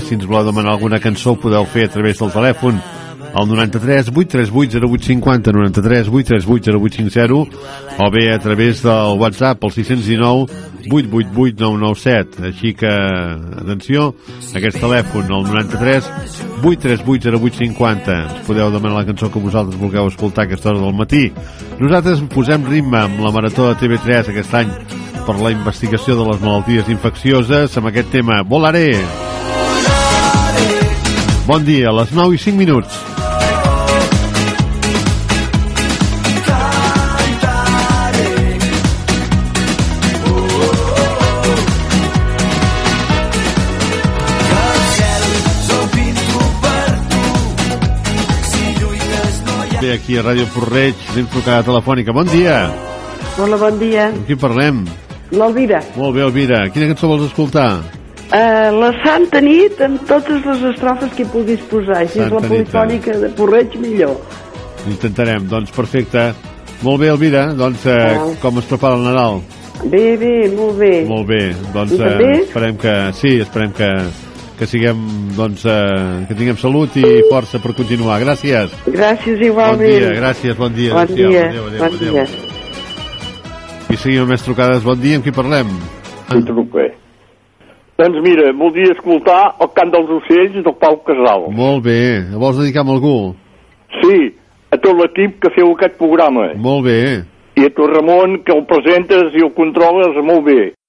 Col·laboració amb la Marató de TV3 dedicada a les malalties infeccioses, trucades telefòniques.
FM
Enregistrament extret del programa "Les Veus dels Pobles" de Ràdio Arrels.